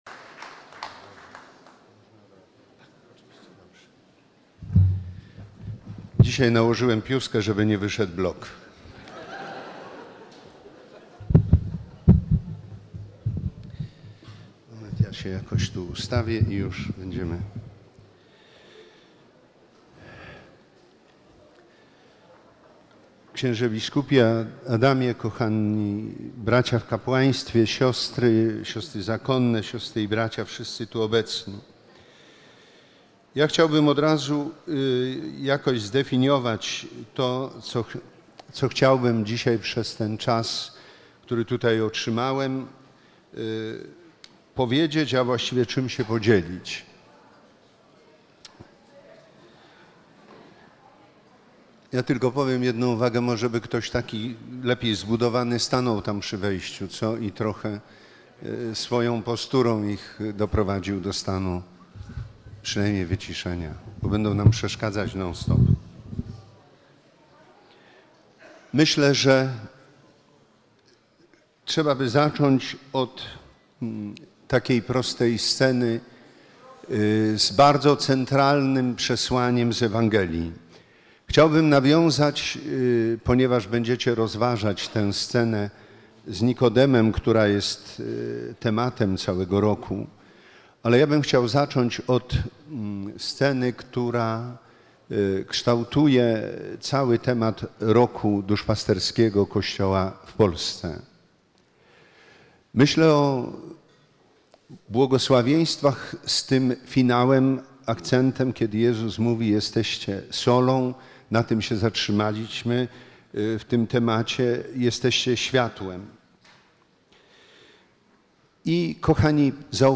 W dniach od 22 do 24 lutego br. odbywała się na Jasnej Górze 38. Kongregacja Odpowiedzialnych Ruchu Światło-Życie.
Edwarda Dajczaka podczas jutrzni (Iz 1, 16-18) Konferencja bp. Edwarda Dajczaka III dzień Kongregacji Piosenka roku 2013/2014
Bp_Dajczak_Kongregacja2013_Konferencja.mp3